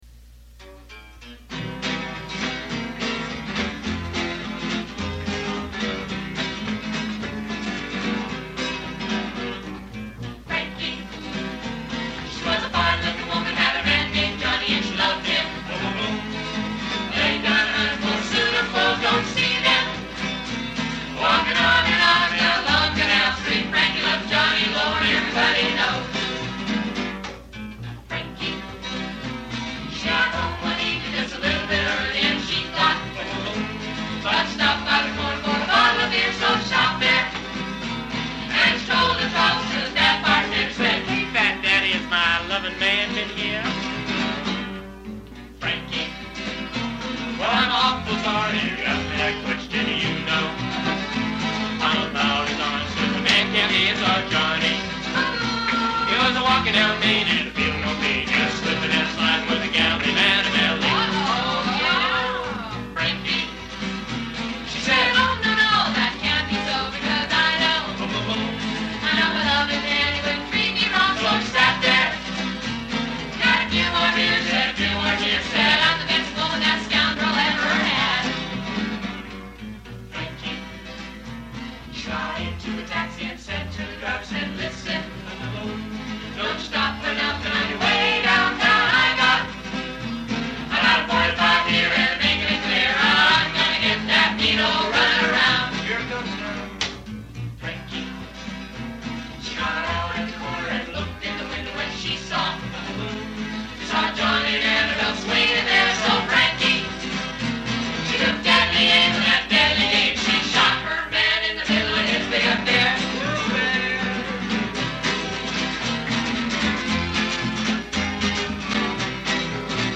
No studio embellishments, just the raw content.
Cassette - New Frankie and Johnny.mp3